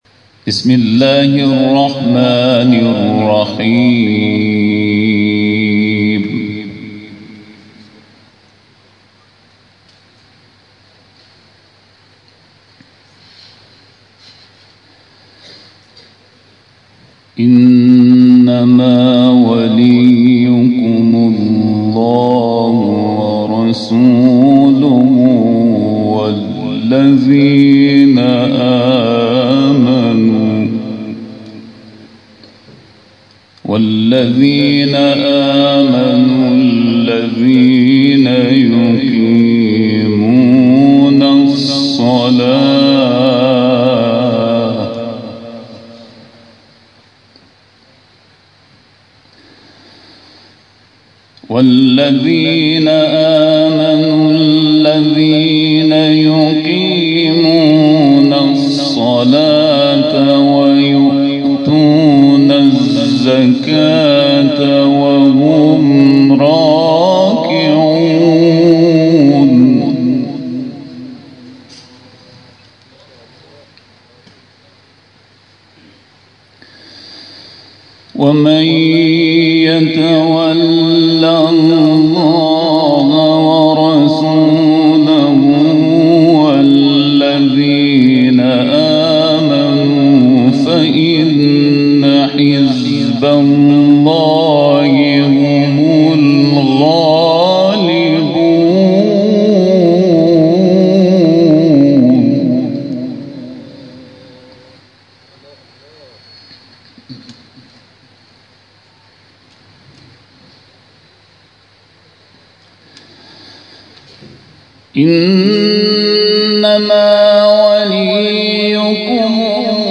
گروه مسابقات: افتتاحیه سی و دومین جشنواره سراسری قرآن و عترت وزارت علوم با حضور آیت‌الله نورمفیدی، نماینده ولی فقیه در استان گلستان و سیدضیاء هاشمی، سرپرست وزارت علوم، تحقیقات و فناوری برگزار شد.